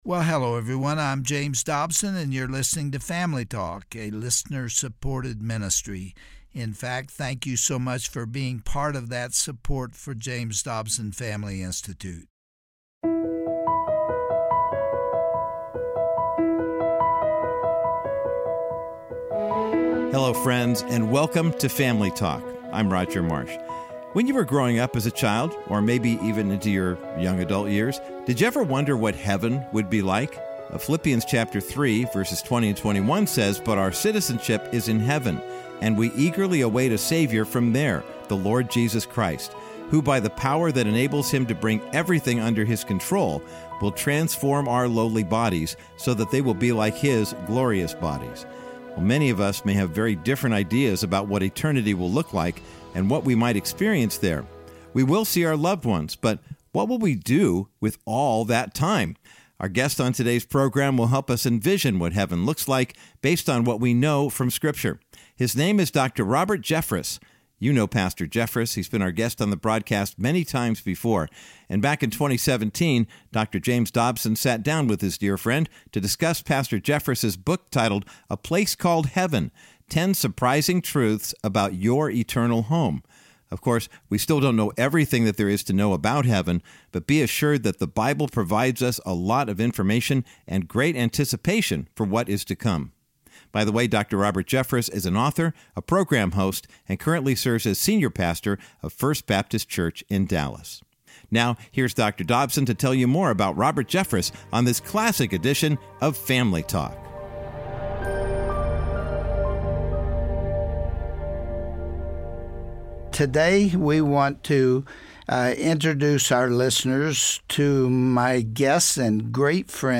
On today’s edition of Family Talk, Dr. James Dobson interviews his friend, Dr. Robert Jeffress, to discuss his book, A Place Called Heaven: 10 Surprising Truths About Your Eternal Home. The two men also talk about age-old topics such as near-death experiences, what our jobs will be in Heaven, and who will be there.